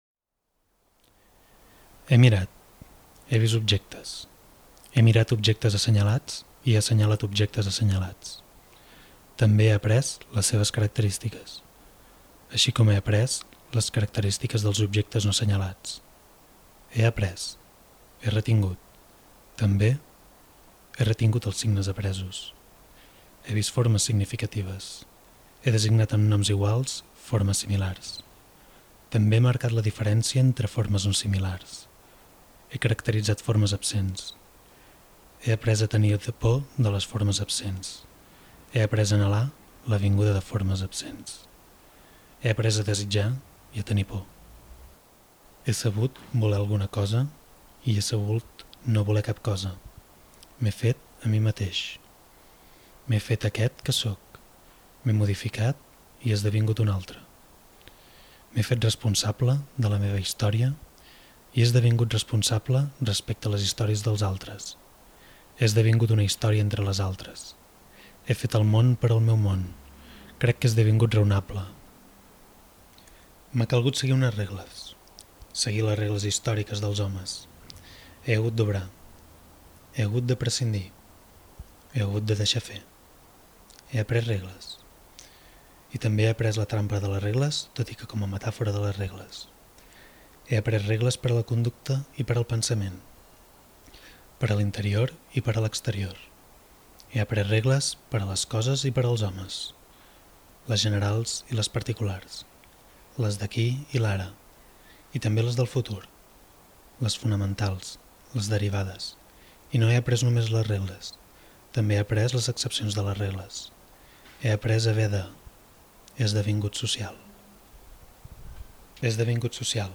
At the entrance of the exhibition is He esdevingut (I’ve become), an audio installation of a male voice interpreting a text by Peter Handke (author, playwright, Austrian director, and exponent of the neoavanguardia). The fragment is taken from Self-Accusation, a piece where the interlocutor explains how he had to learn a series of behavior patterns and rules in order to become a social being.